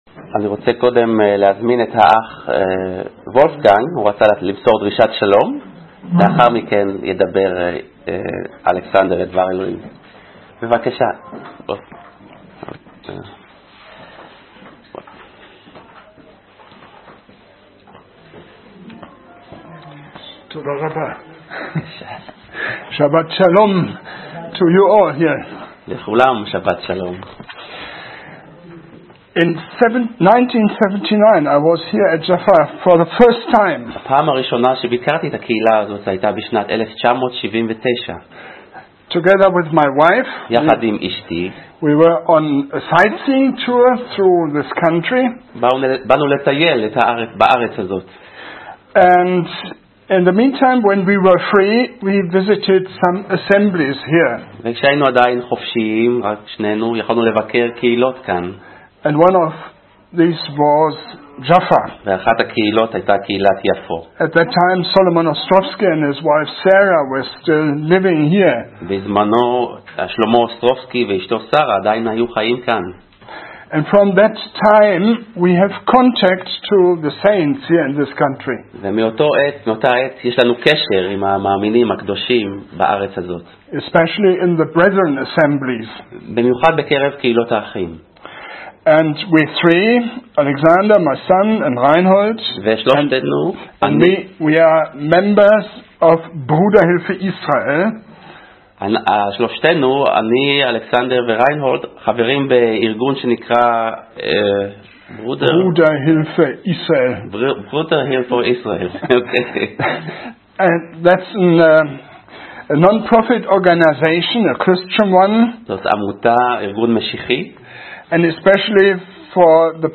אפריל 10, 2019 דרשות לפי נושאים